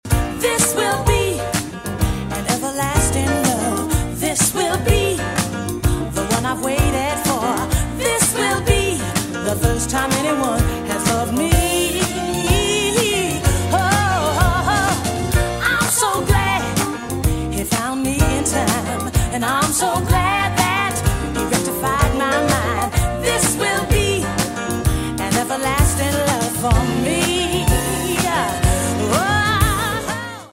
✅ En el Museo Regional del Trapiche de San Jerónimo, se llevó acabo una obra de teatro titulada “Aprendiendo de Derechos Humanos” con el objetivo de facilitar el conocimiento y la reflexión sobre los derechos de una manera atractiva y cercana, siendo una herramienta educativa muy útil para promover la sensibilización sobre estos temas, especialmente entre los niños y jóvenes.